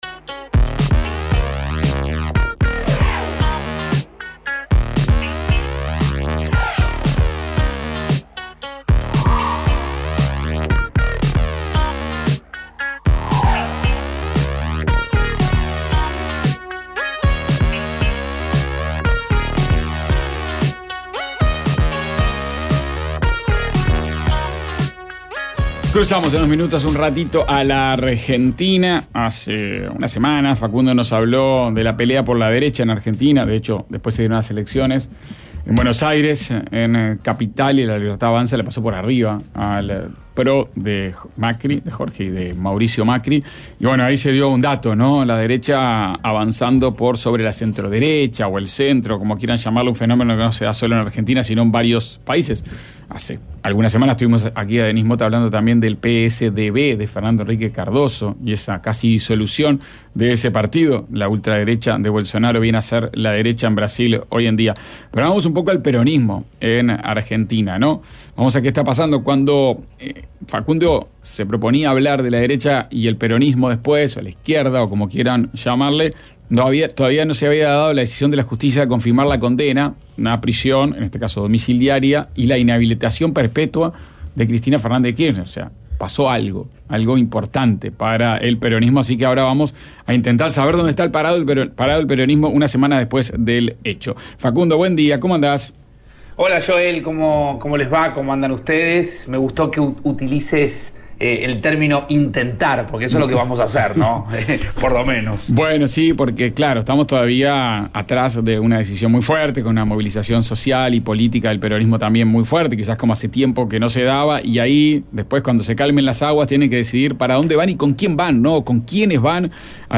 Columna de Facundo Pastor, desde Buenos Aires.